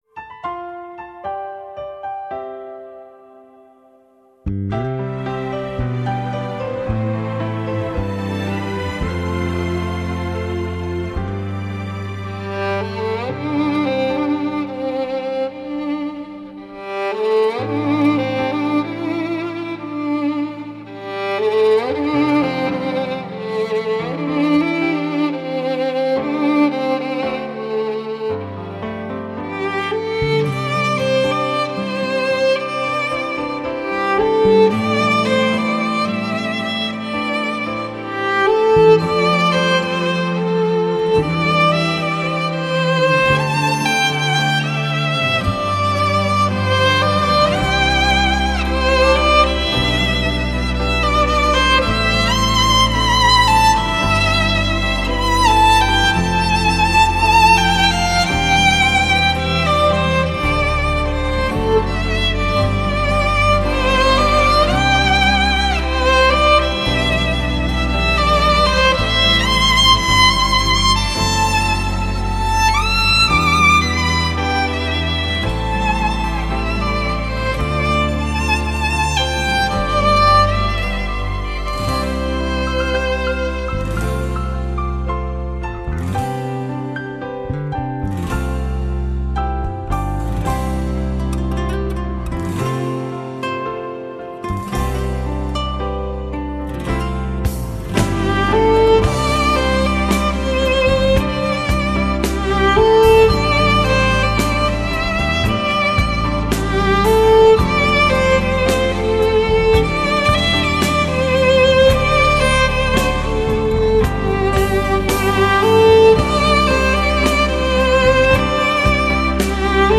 优雅小提琴
用优雅的小提琴对流行音乐进行全新演绎。